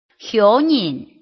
臺灣客語拼音學習網-客語聽讀拼-南四縣腔-開尾韻